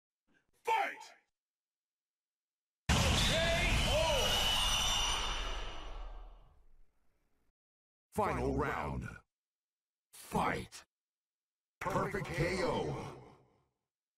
Streetfighter KO, Perfect KO and